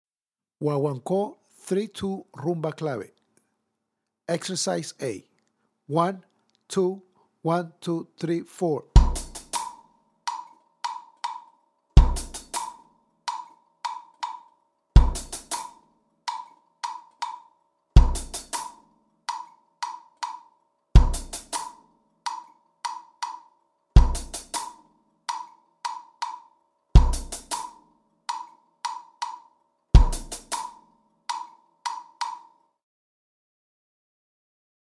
Méthode pour Batterie